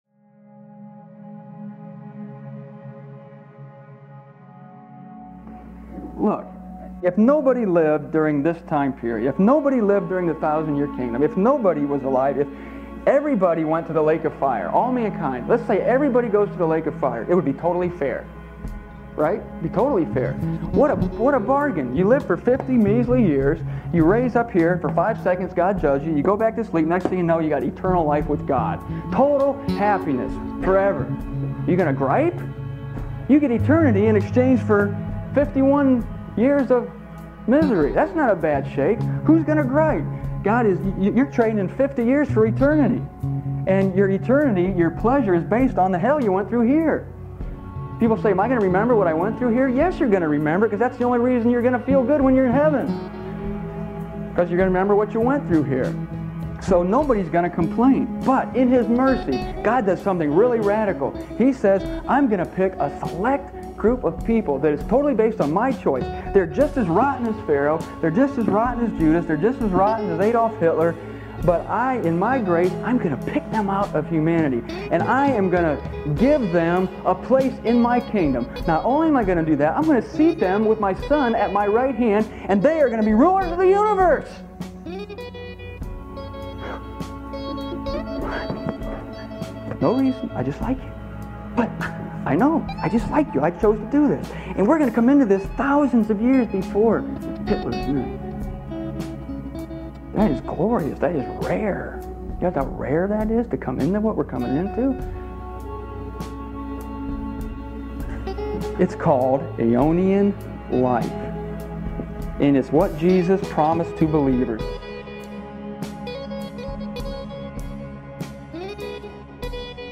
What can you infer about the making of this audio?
My family and I drove up to Sudbury, Ontario, Canada in 2000, where I was invited to deliver a series of messages. The chief topic that weekend was death.